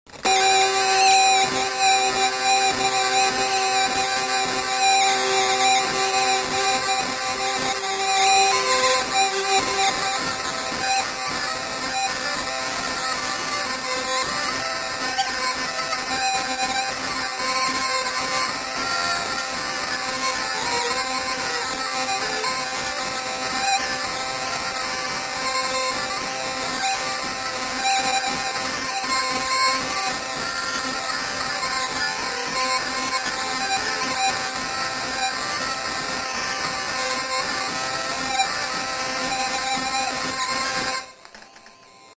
Aire culturelle : Gabardan
Lieu : Oeyreluy
Genre : morceau instrumental
Instrument de musique : vielle à roue
Danse : polka